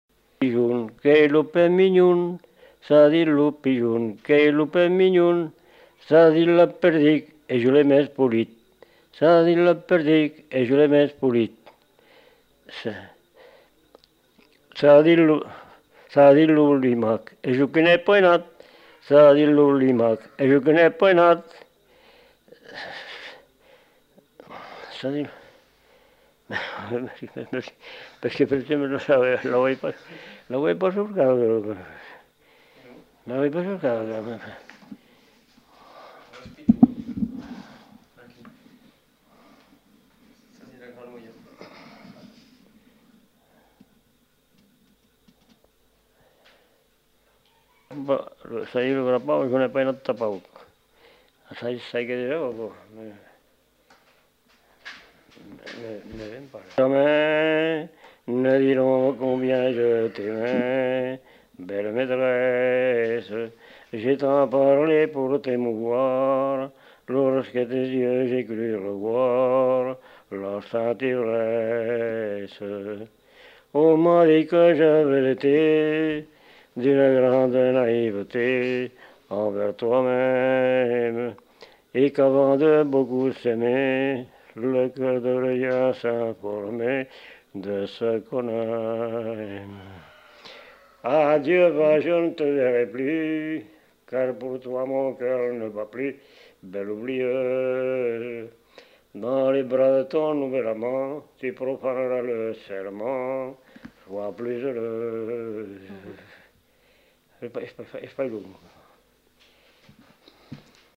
Aire culturelle : Lomagne
Lieu : Faudoas
Genre : chant
Effectif : 1
Type de voix : voix d'homme
Production du son : chanté
Notes consultables : Suit un fragment de chant dont l'incipit n'est pas enregistré.